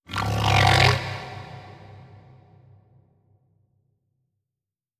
khloCritter_Male27-Verb.wav